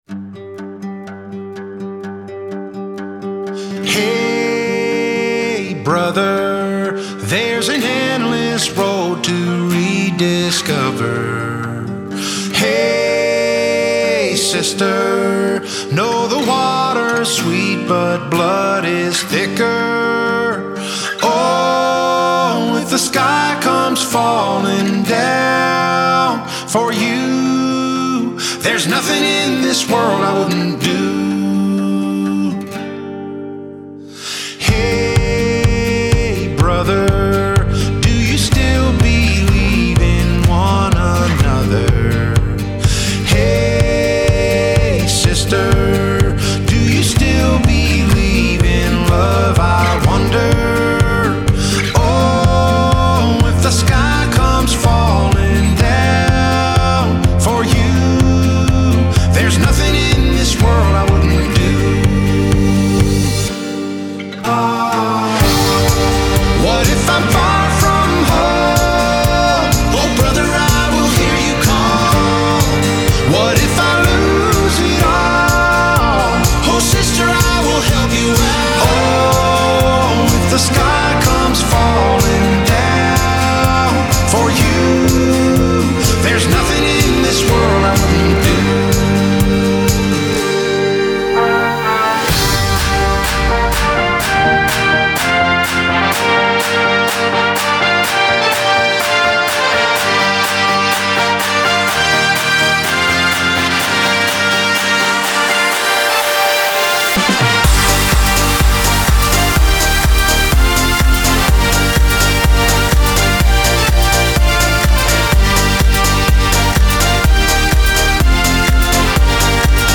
dance song